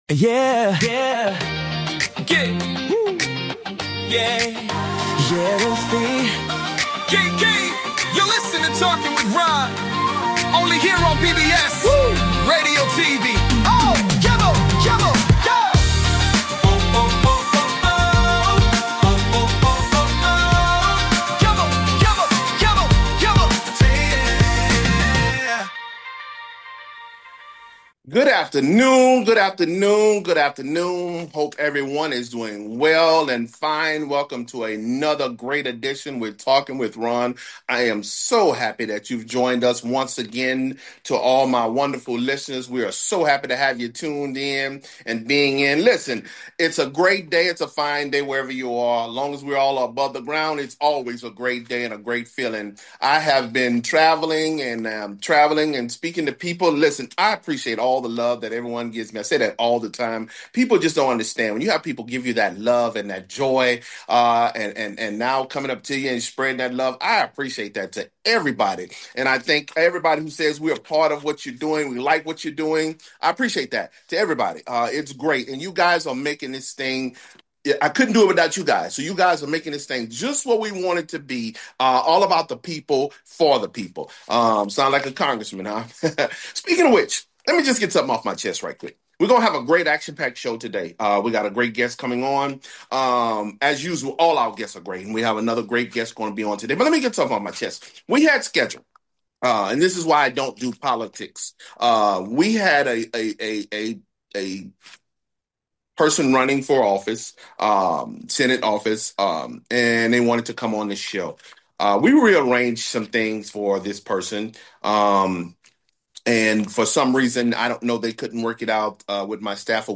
Guest, Sam Bettens